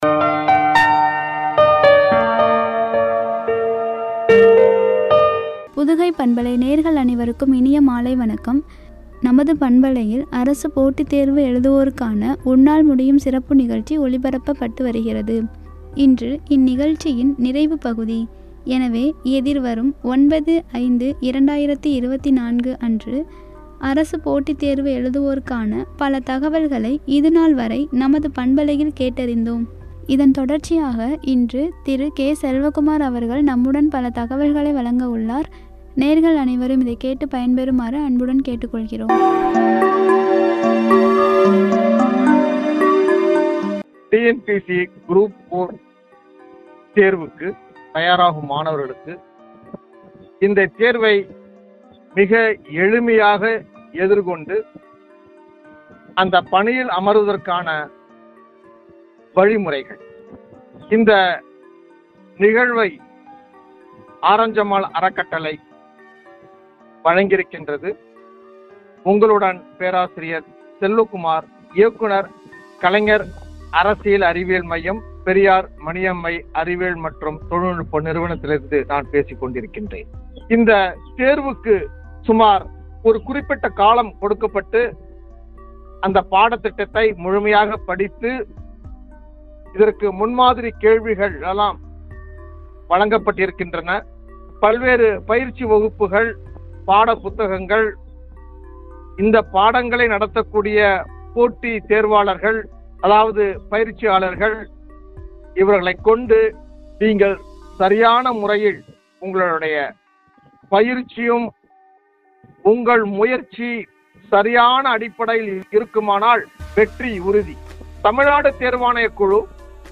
உரை.